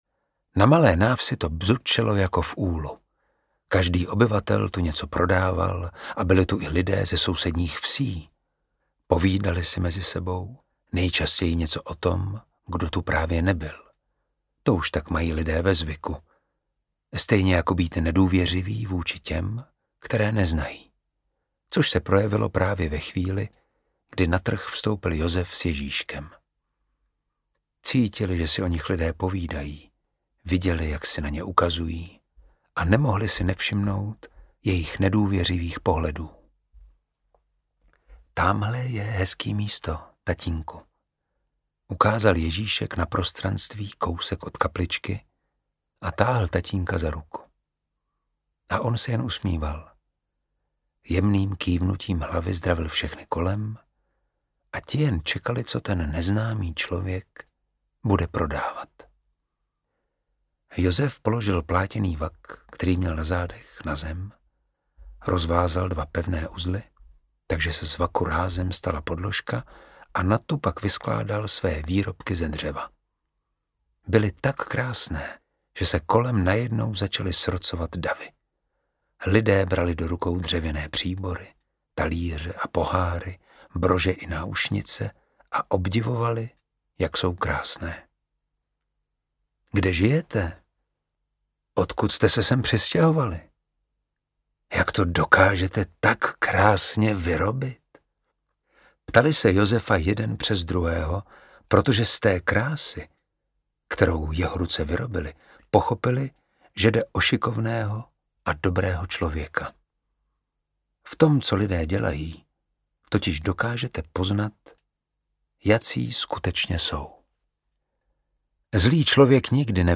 Ukázka z knihy
• InterpretDavid Švehlík